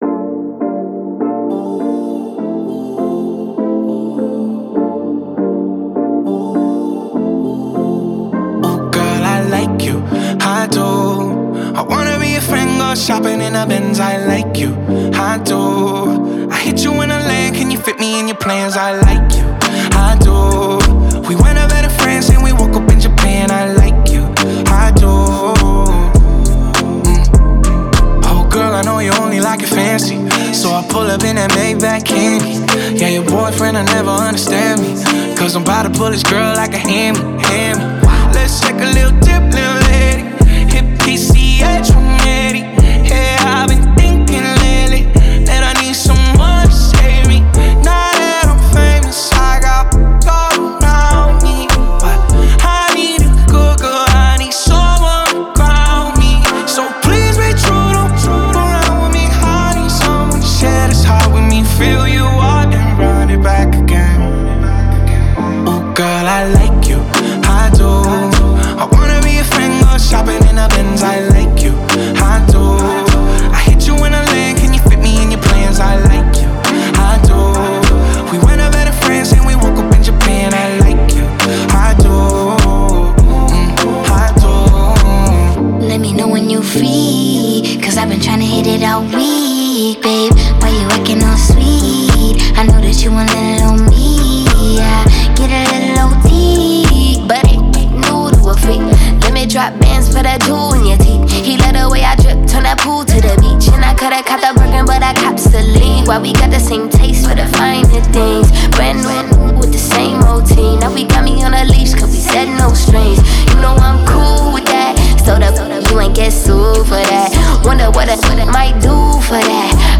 Genre: Hip-Hop.